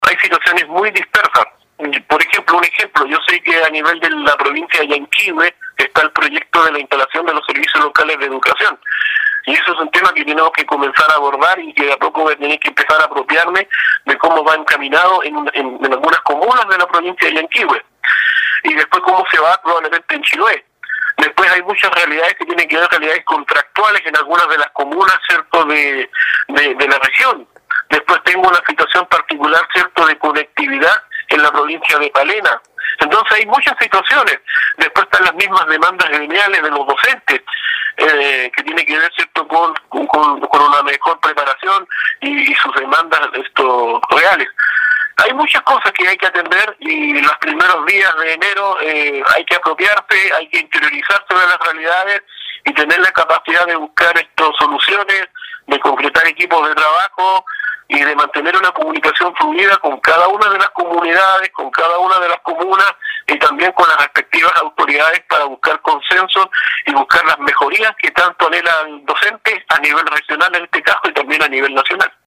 En diálogo con radio Estrella del Mar, sostuvo que espera asumir el cargo conociendo en primera instancia la situación de la institución y del estado de cada una de las comunas que componen esta importante entidad.